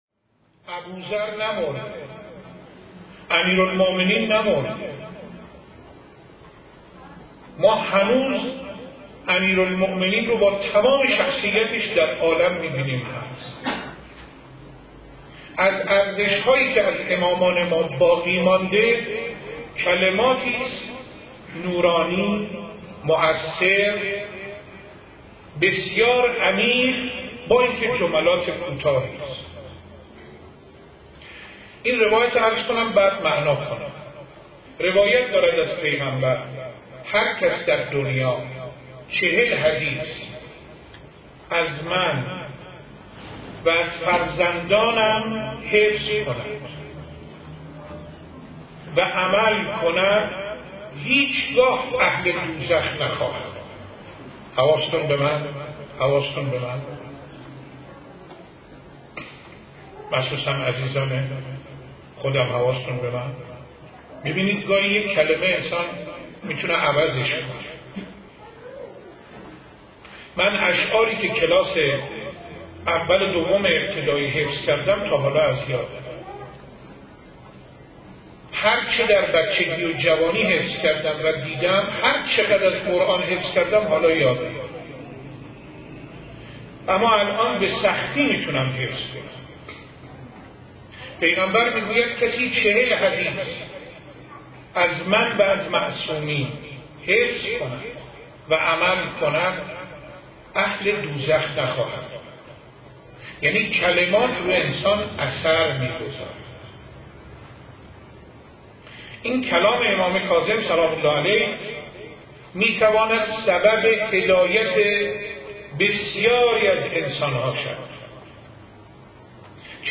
صوت سخنرانی